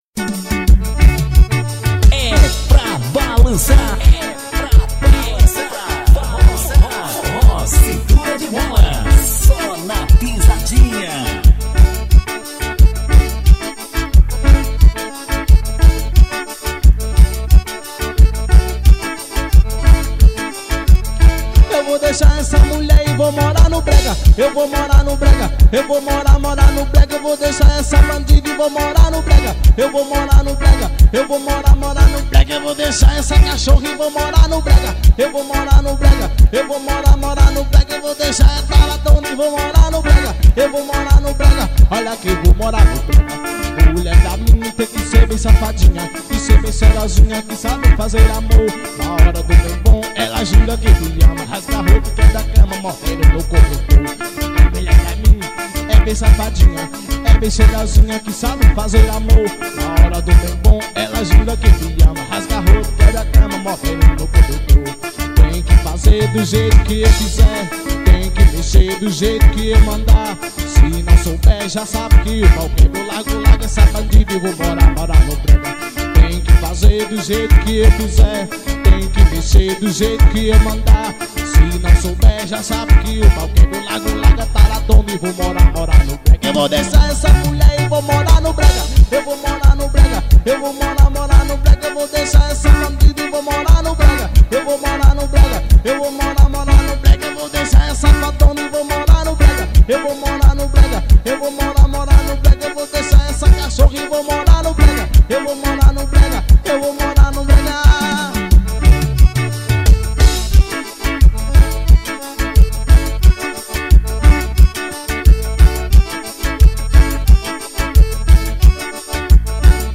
2024-02-15 00:28:15 Gênero: Forró Views